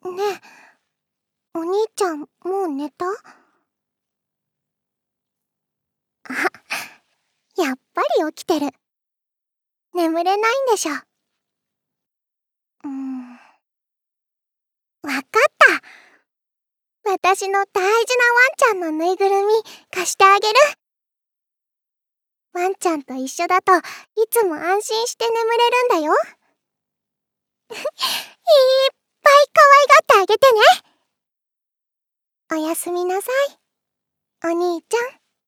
ボイスサンプル2.mp3